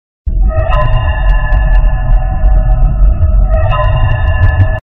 Fnaf 2 Hallway Ambience Meme